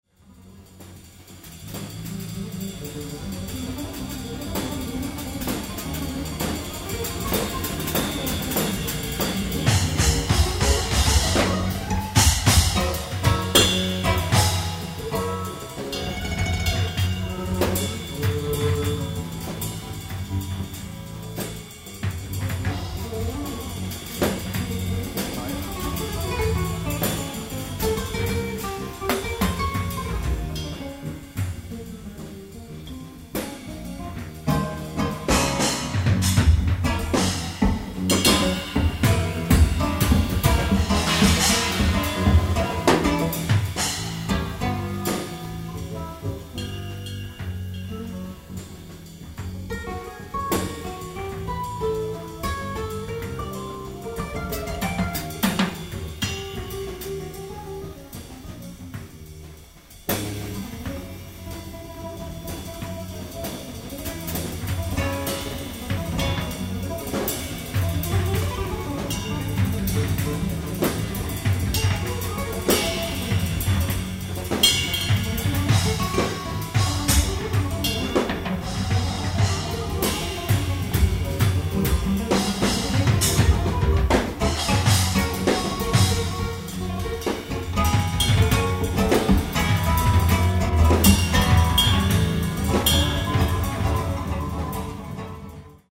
ライブ・アット・ボトムライン・クラブ、ニューヨーク、NY 07/03/1991(early show)
※試聴用に実際より音質を落としています。